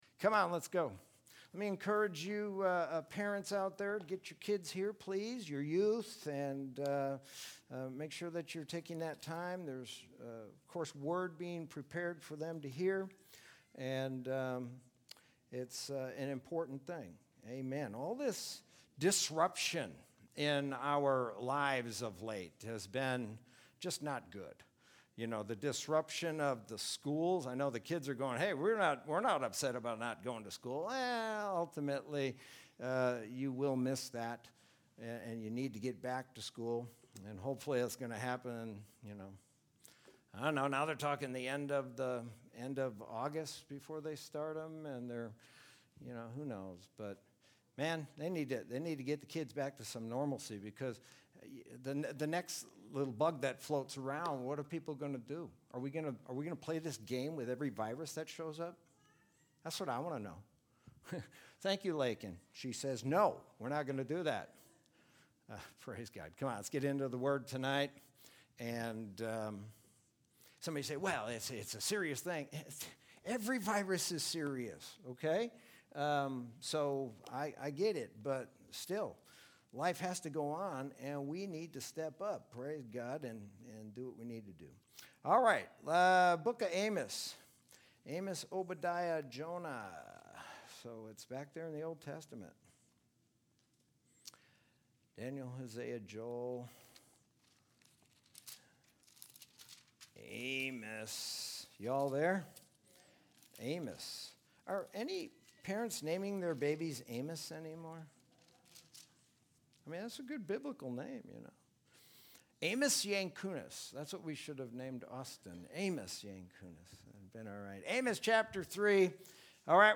Sermon from Wednesday, July 22, 2020.